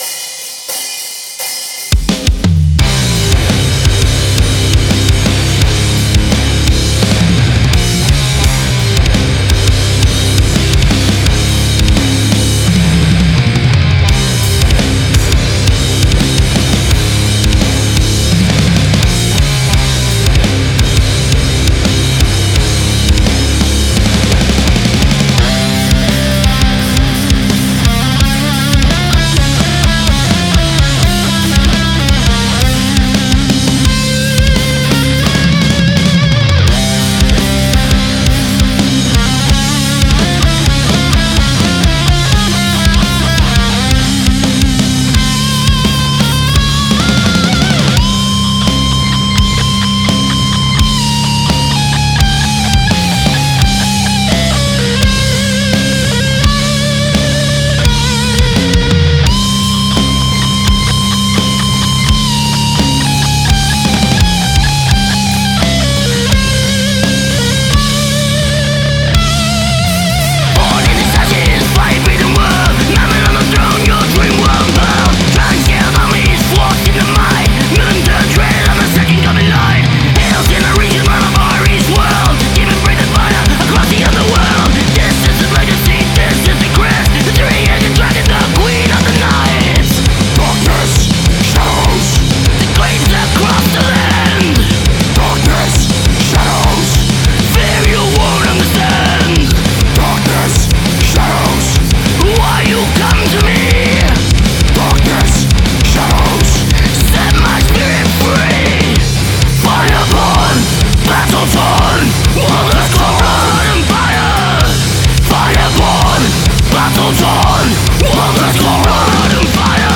thrash metal band